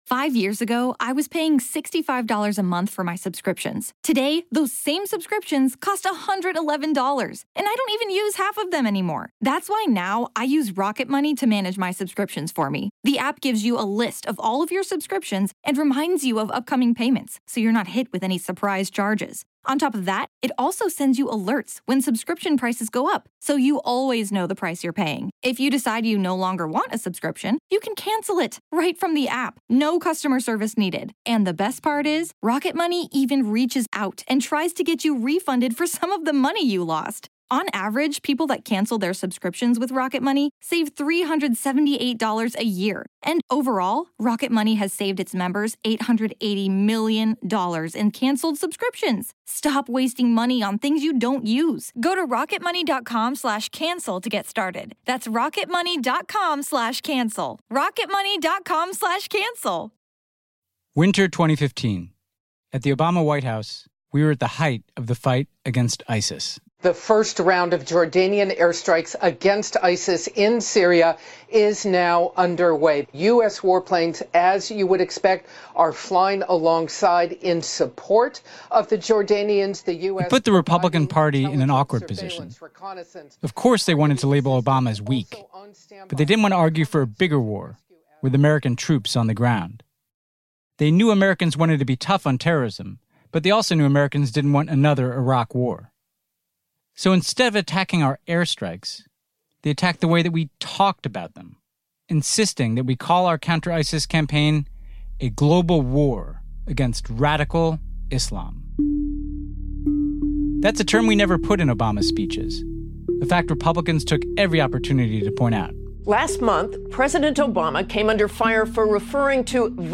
Host Ben Rhodes talks to investigative journalist Rana Ayyub, who went undercover and exposed Modi’s tactics to turn Indians against one another.